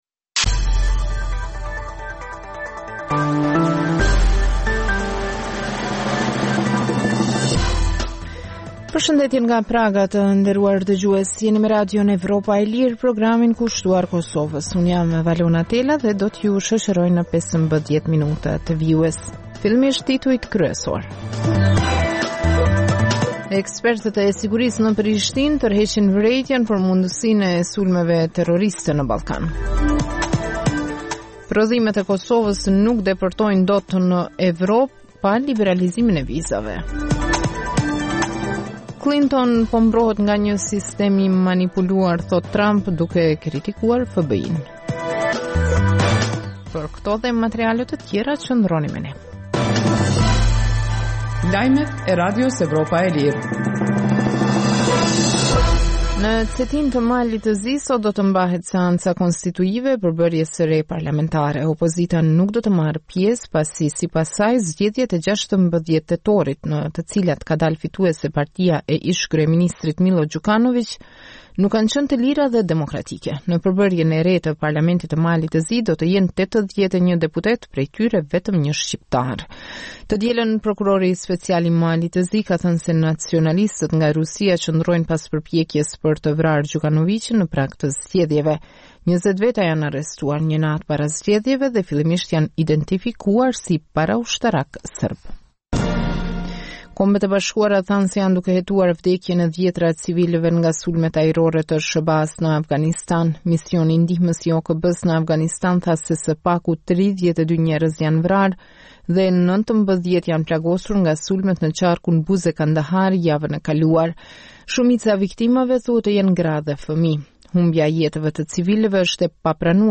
Emisioni i mesditës fillon me buletinin e lajmeve që kanë të bëjnë me zhvillimet e fundit në Kosovë, rajon dhe botë. Në këtë emision sjellim raporte dhe kronika të ditës, por edhe tema aktuale nga zhvillimet politike dhe ekonomike. Emisioni i mesditës në të shumtën e rasteve sjellë artikuj nga shtypi perendimor, por edhe intervista me analistë të njohur ndërkombëtar kushtuar zhvillimeve në Kosovë dhe më gjërë.